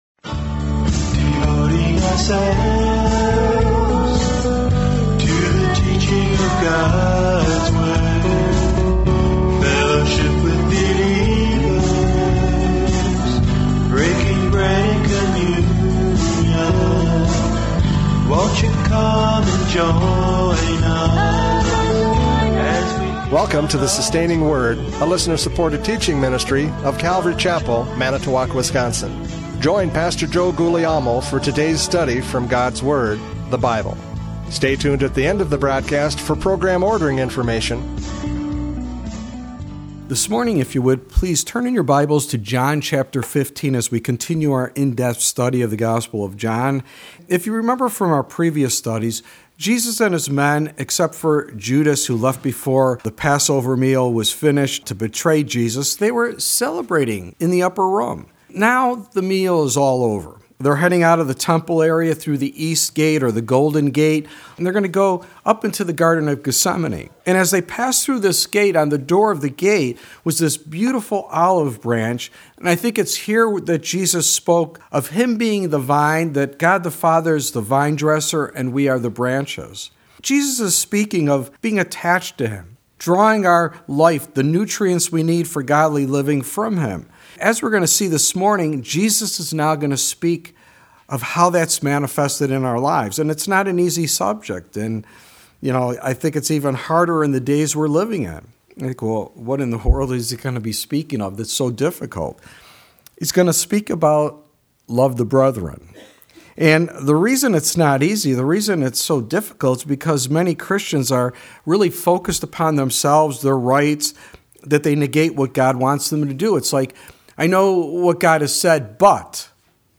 John 15:12-17 Service Type: Radio Programs « John 15:1-11 “I Am the Vine!”